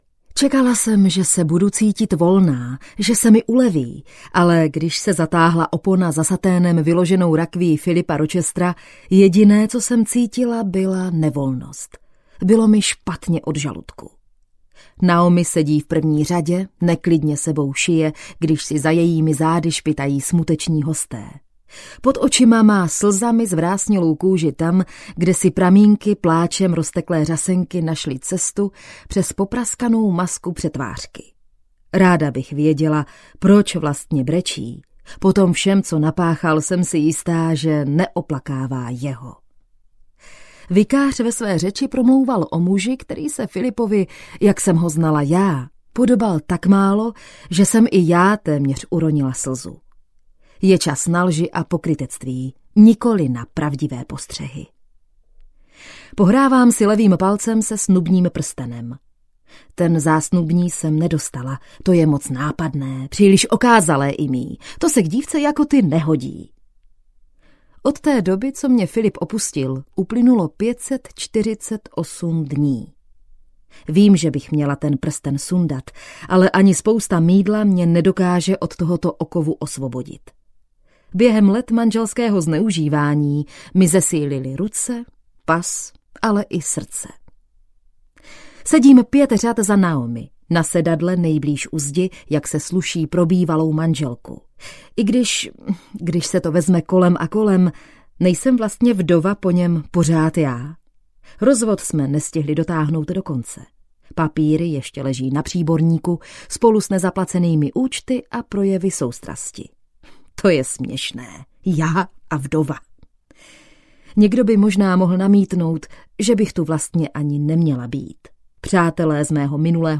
Náš manžel audiokniha
Ukázka z knihy